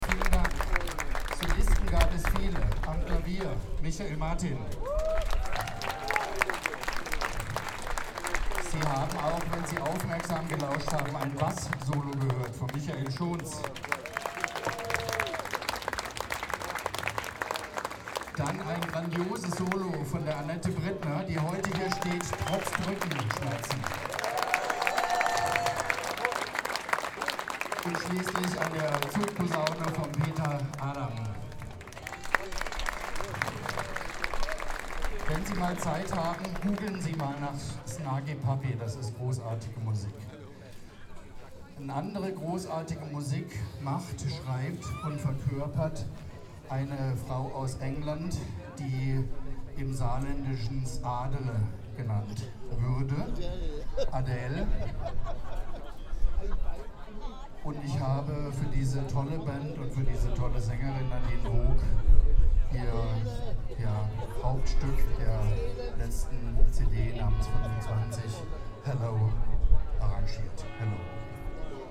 12 - Ansage.mp3